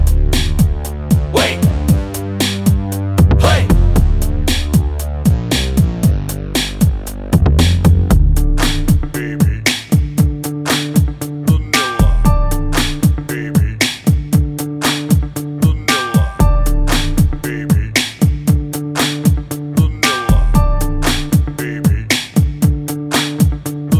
no Backing Vocals R'n'B